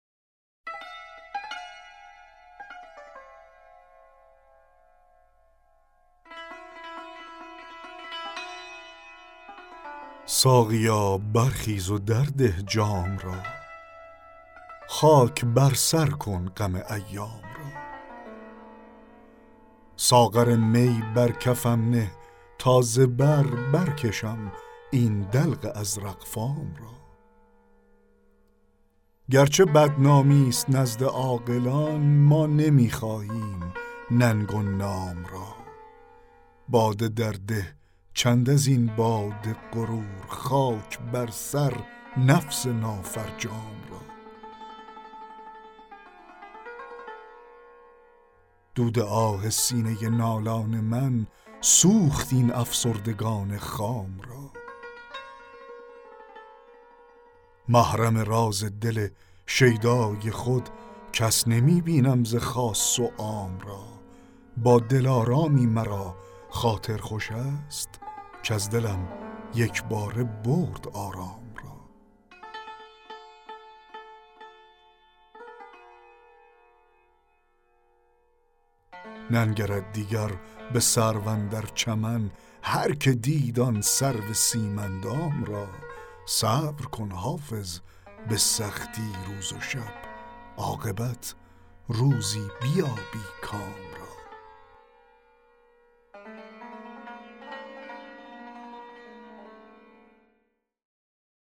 دکلمه غزل 8 حافظ
غزل-8-جلیل-شهناز.mp3